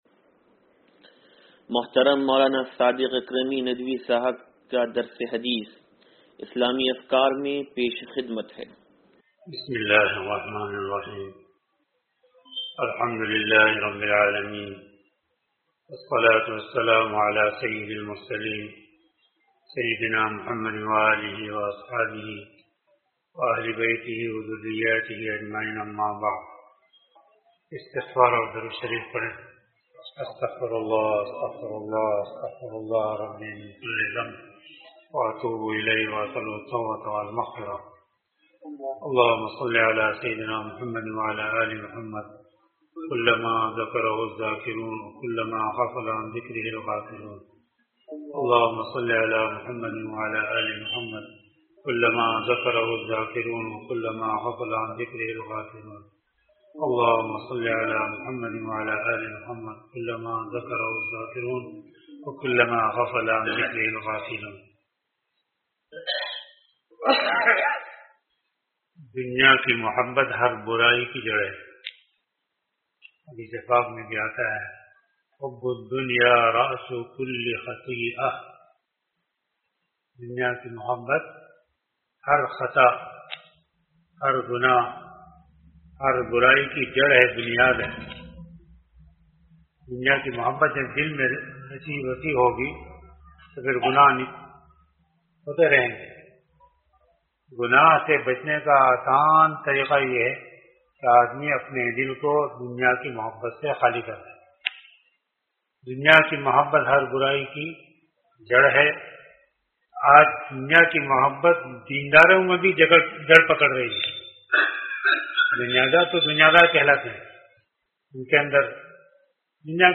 درس حدیث نمبر 0481